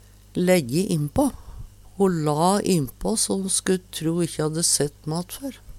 lejje innpå - Numedalsmål (en-US)